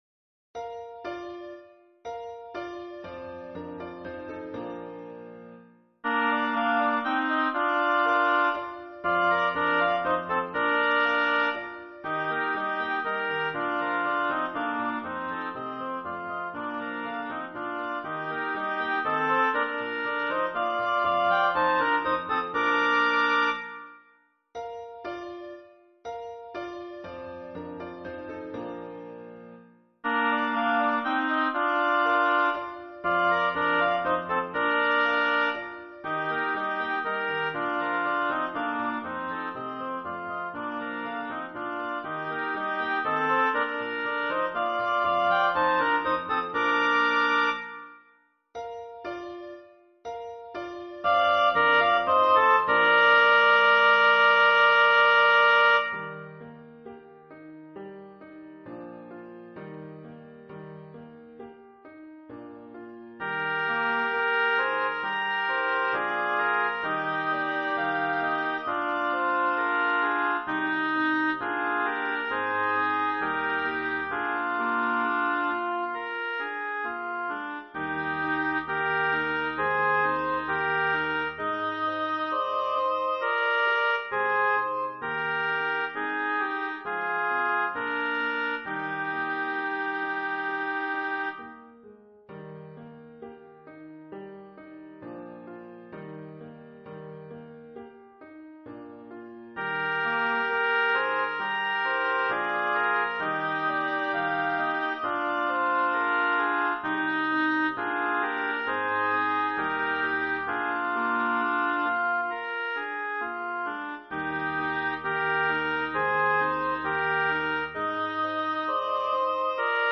A duet for alto saxes and piano and is presented as a suite.
Eb
3-4, 4-4, cut-time
Suite-three movements